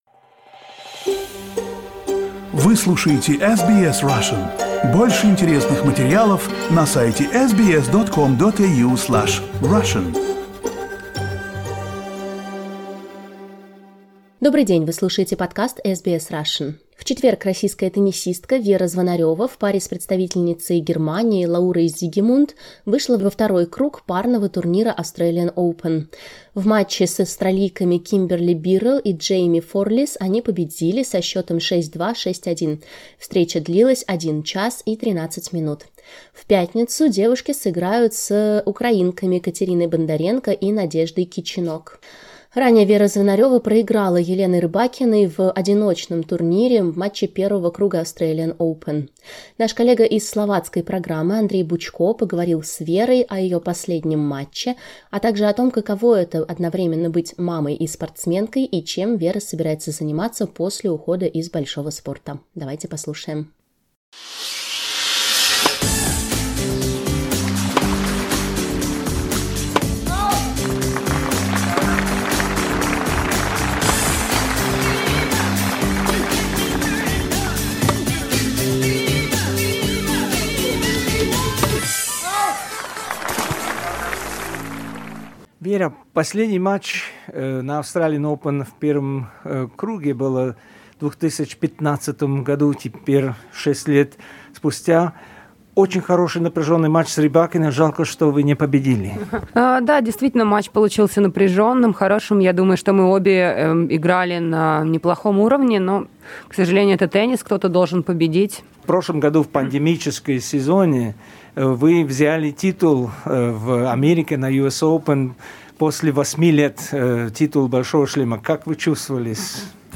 Exclusive: Interview with Vera Zvonareva